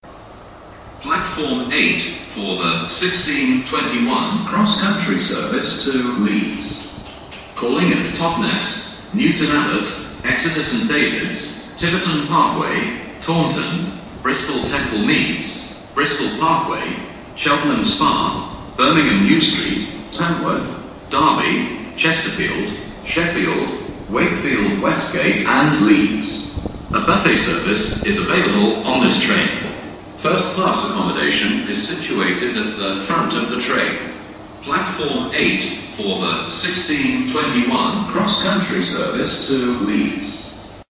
描述：Just a simple recording of an HST whizzing past me at about 100MPH accelerating to 125MPH however
标签： first great high hst pass railway speed train western
声道立体声